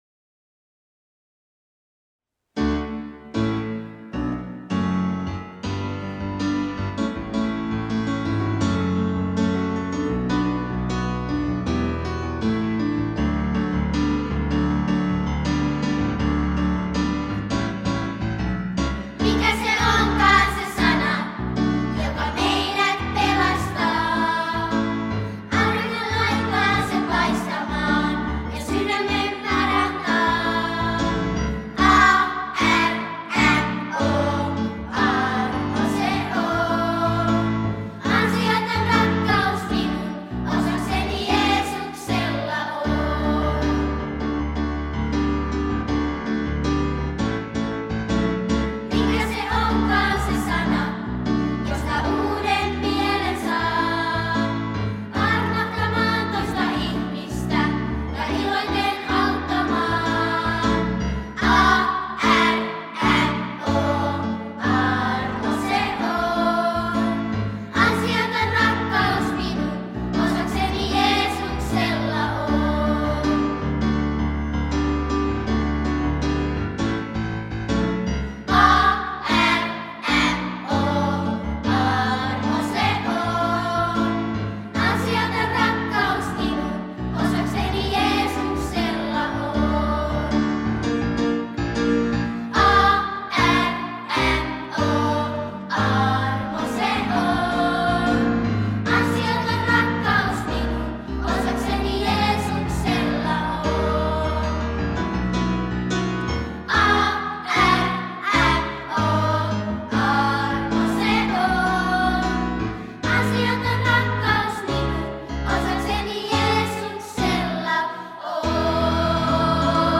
lasten evankelinen laulukirja.